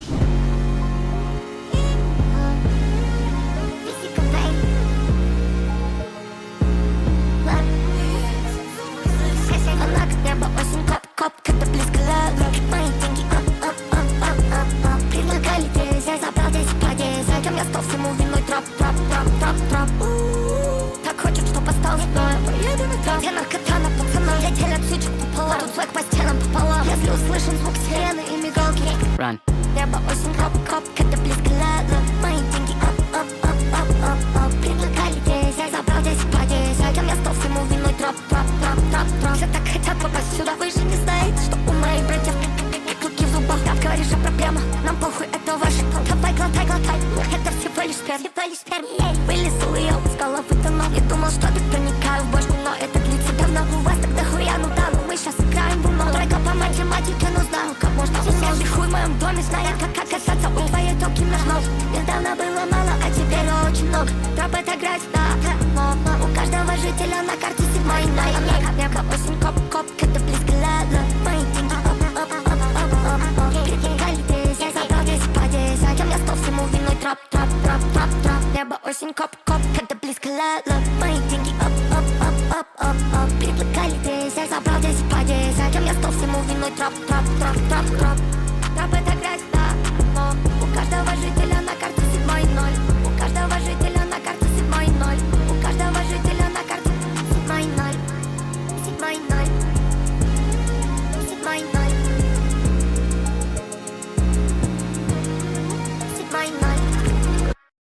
Слушать Trap музыку для фона (без авторских прав)
• Категория: Музыка в стиле Trap
• Качество: Высокое